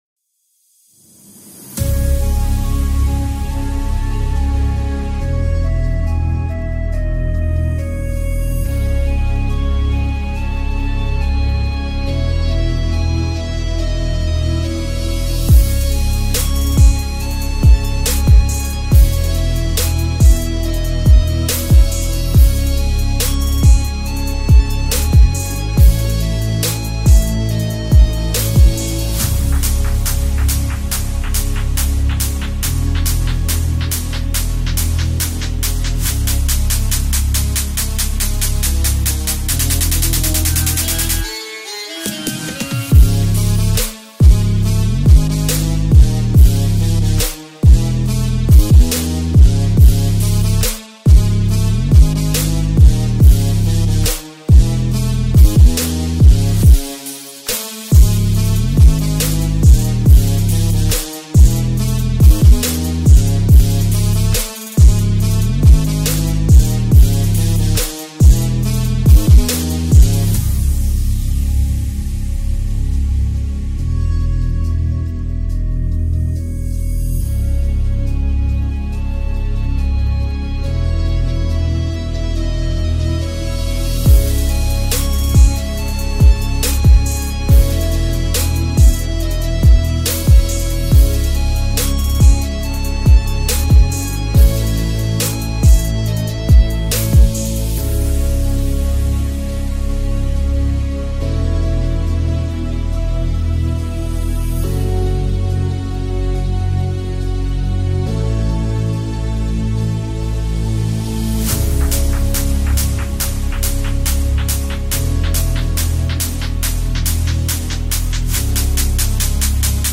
آهنگ فاز بالا خارجی سیستمی بیس سنگین